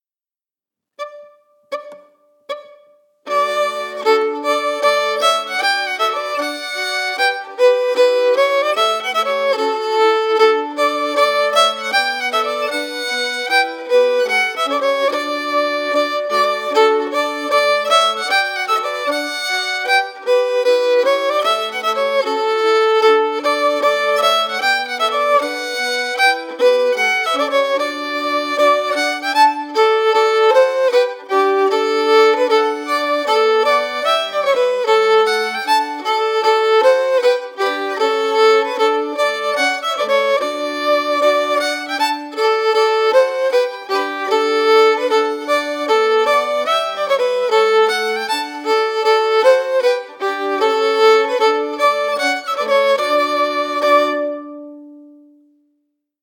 Key: D
Form:March
Region:Scotland